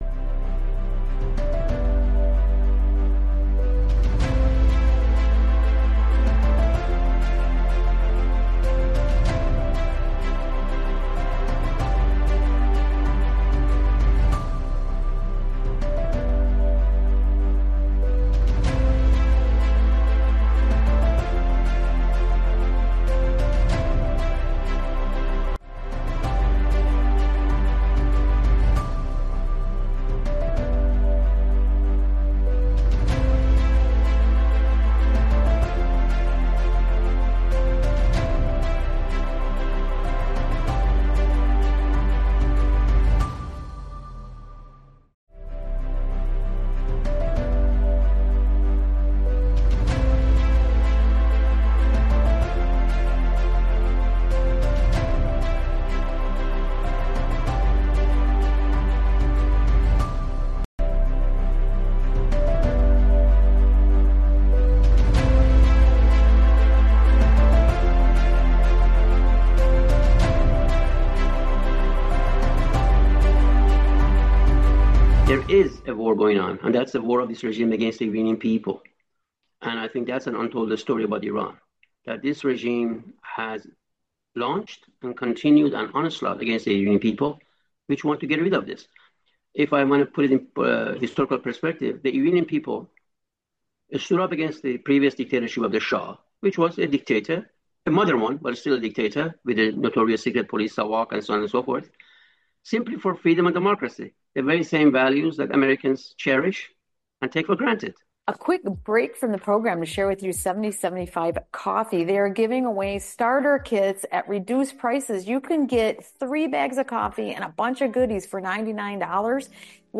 The show features an interview with an Iranian freedom fighter from the National Council of Resistance of Iran, the largest resistance movement in the country.